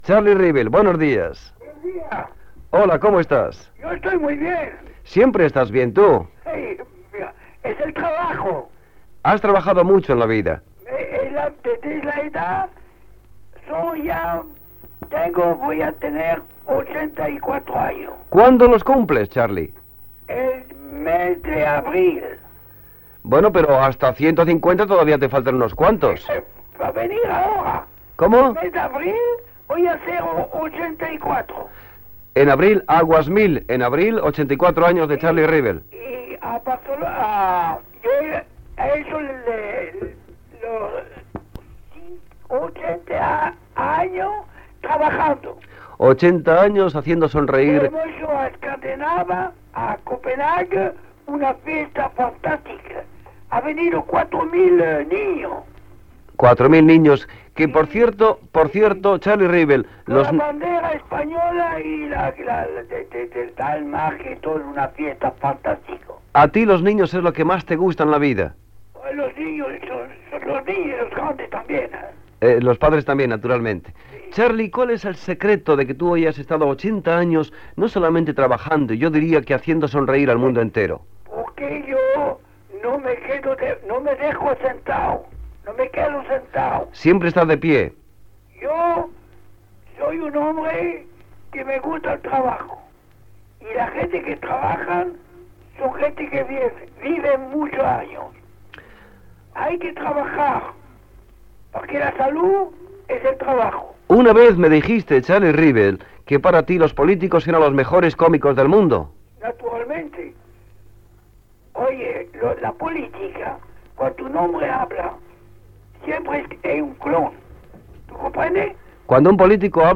Entrevista al pallasso Charlie Rivel (Josep Andreu)